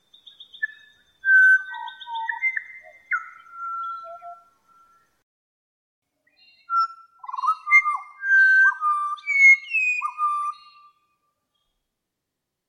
It sounded like a jazz flautist in a tree.
Low, slow, rich, and enticingly familiar – these phrases were an epiphany to me.
Listen: Pied butcherbird duet: DownloadMP3 / 210 KB